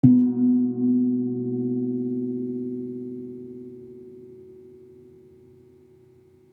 Gong-B2-f.wav